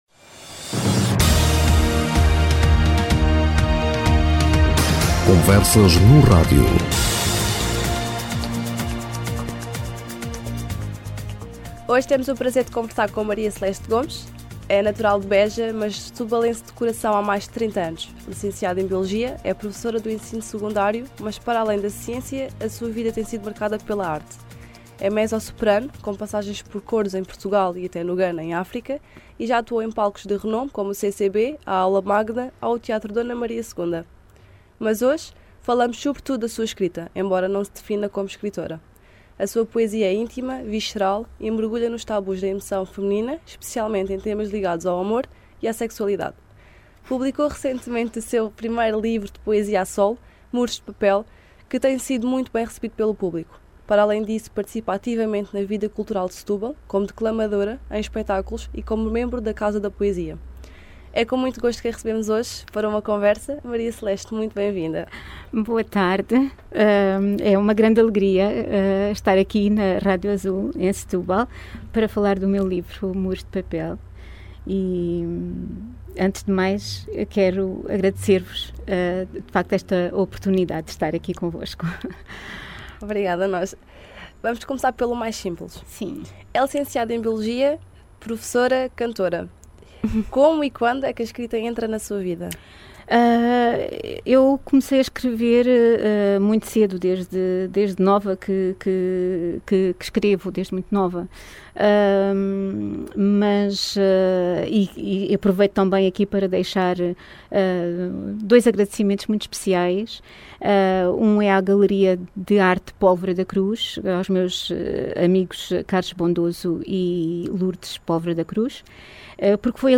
Oiça aqui a entrevista completa. https